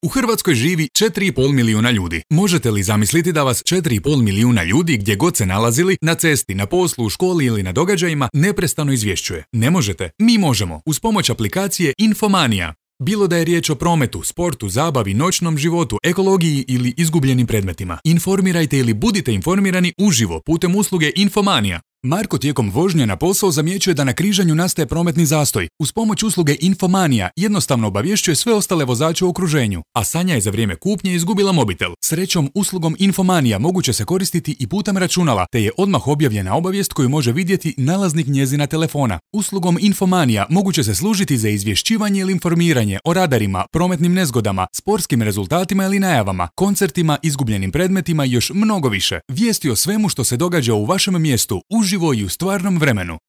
Croatian voice over talent with positive and pleasant voice and a neutral accent with over 10 years of experience in broadcasting.
Sprechprobe: eLearning (Muttersprache):